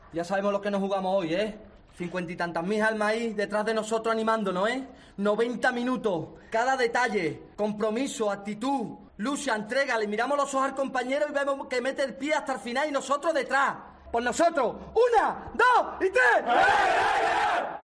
La arenga de Joaquín antes del derbi ante el Sevilla
El jugador bético, que marcó el gol de la victoria, actuó de capitán y animó a sus compañeros antes del incio del partido en el Villamarín.